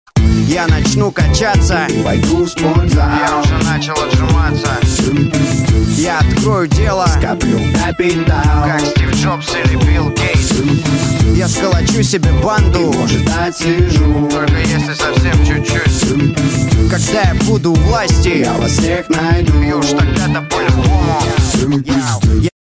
• Качество: 128, Stereo
русский рэп
мотивирующие